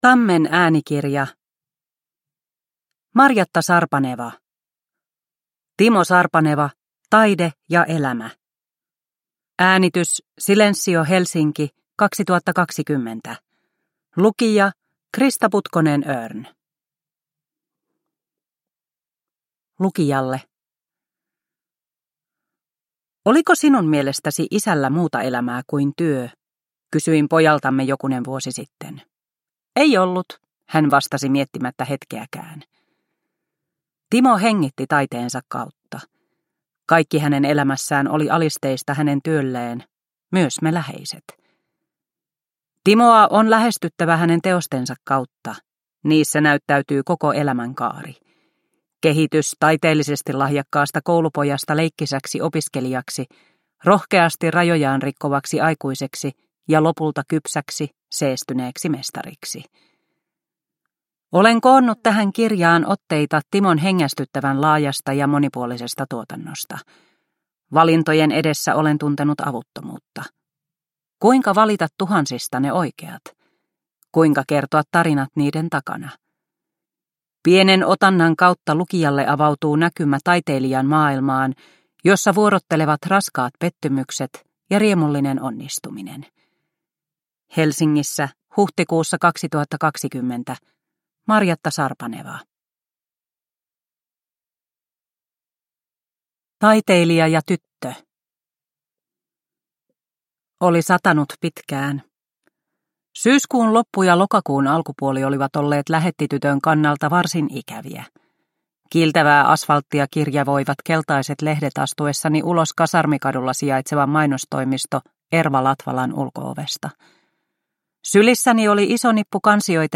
Timo Sarpaneva – Ljudbok – Laddas ner